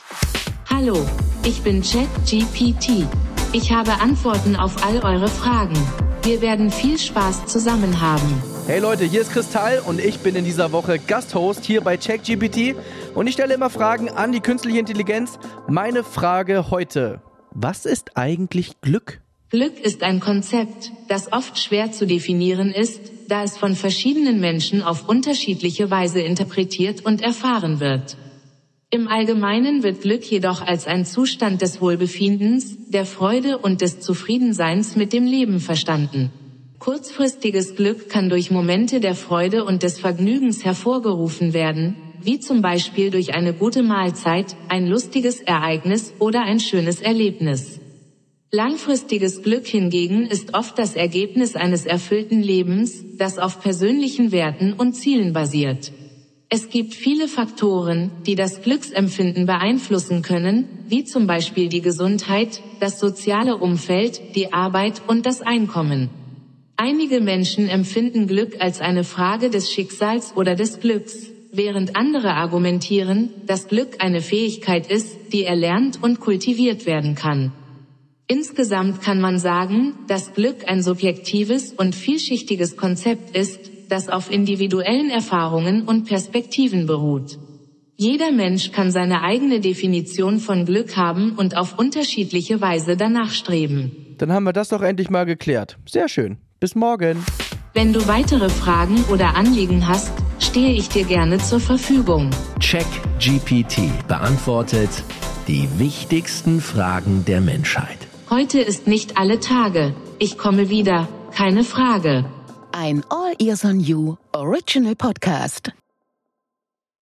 Chris Tall & KI